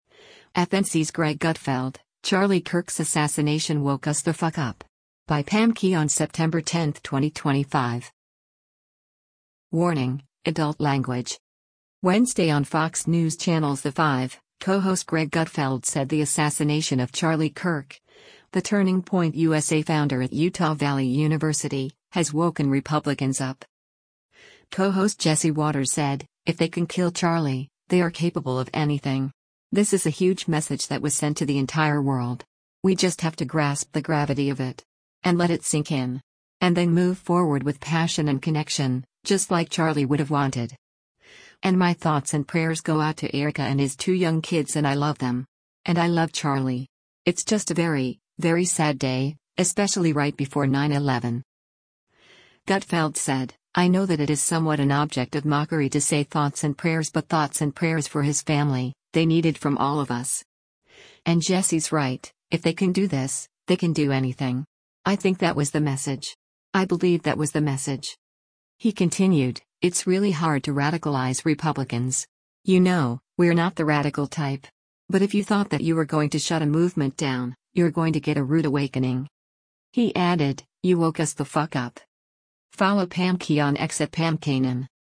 [WARNING: Adult Language]
Wednesday on Fox News Channel’s “The Five,” co-host Greg Gutfeld said the assassination of Charlie Kirk, the Turning Point USA founder at Utah Valley University, has woken Republicans up.